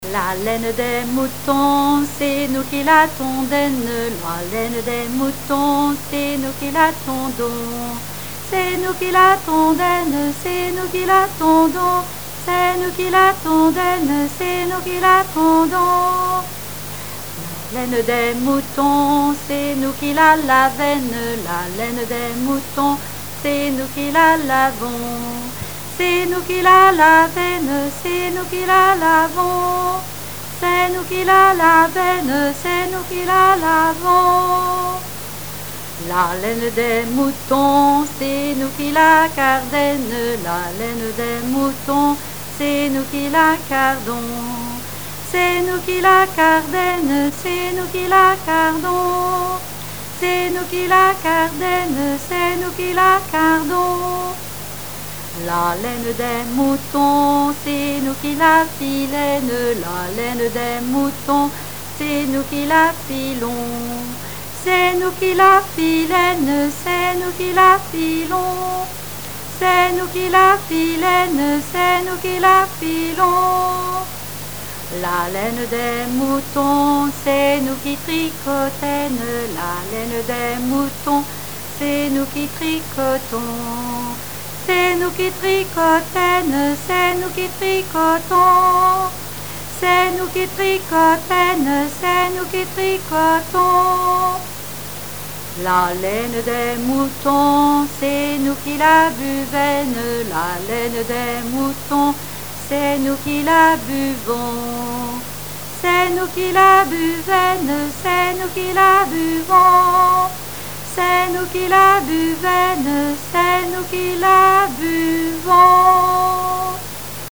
Genre laisse
Catégorie Pièce musicale inédite